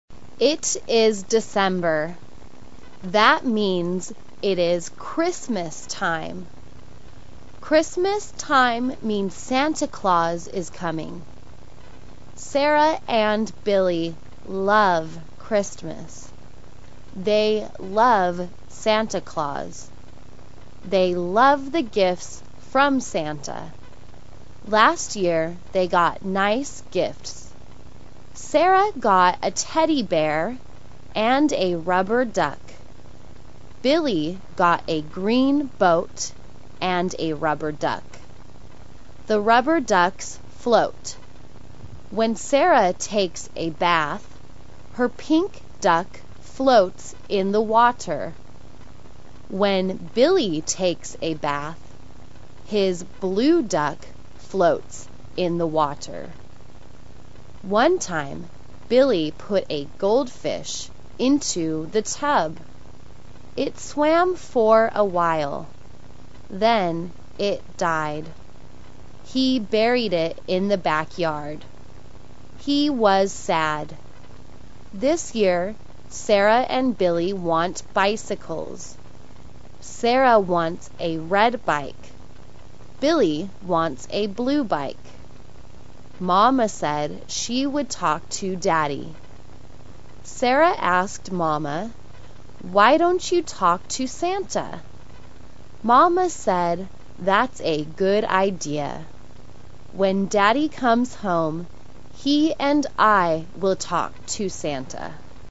慢速英语短文听力(MP3+中英字幕) 第206期:去问圣诞老人MP3音频下载,12月了。